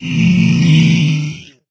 zpigdeath.ogg